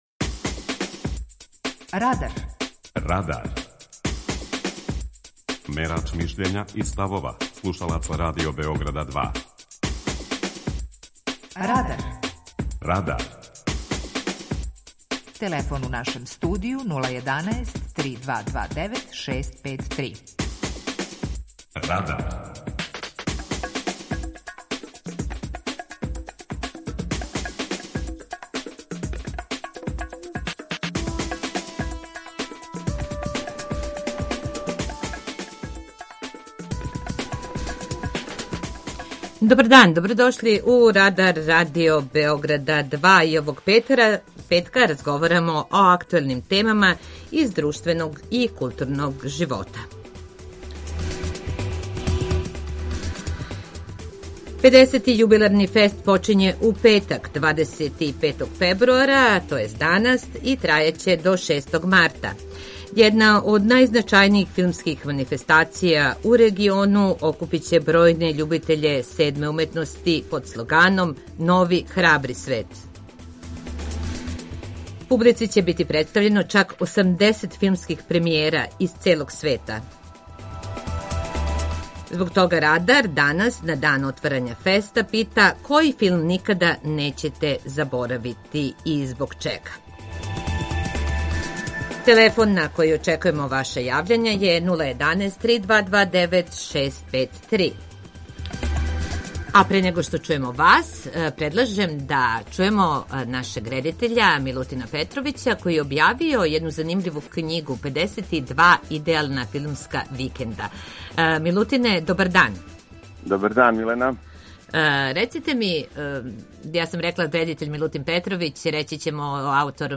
Питање Радара: Који филм нећете никада заборавити? преузми : 19.08 MB Радар Autor: Група аутора У емисији „Радар", гости и слушаоци разговарају о актуелним темама из друштвеног и културног живота.